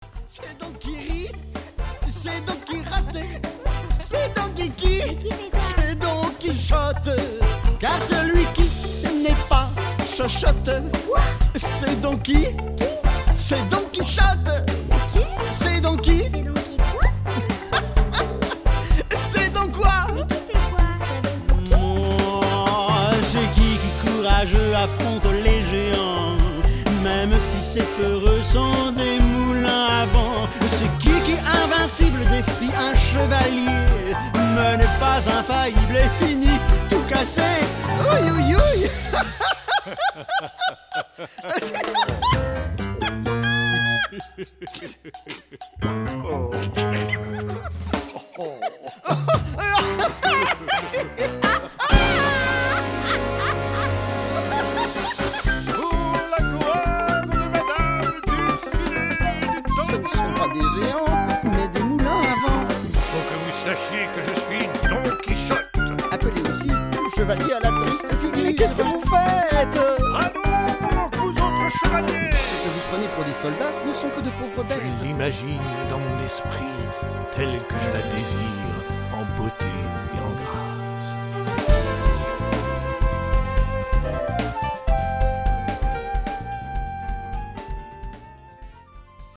Plus qu'un simple conte, ce spectacle musical nous amène à nous questionner sur l'espace entre le rêve et la réalité.
Le Diaporama de l'enregistrement du CD